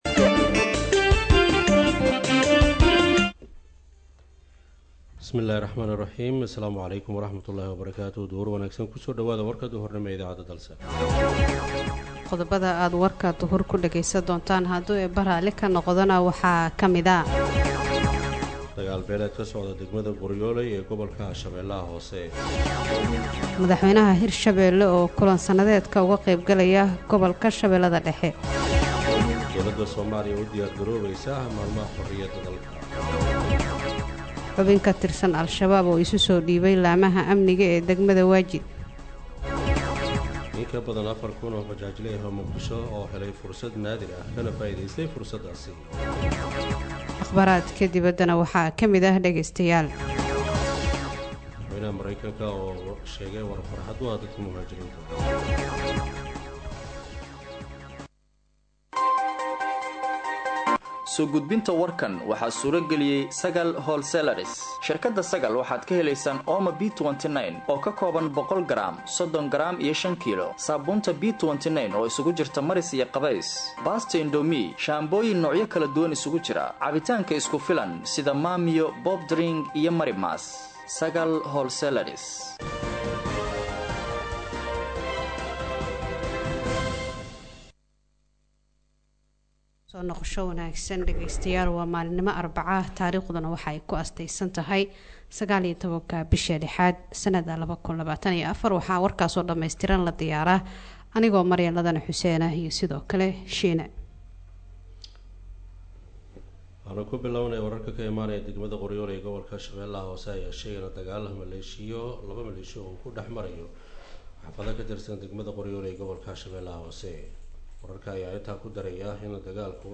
HalkanÂ Ka Dhageyso Warka Duhurnimo Ee Radio Dalsan: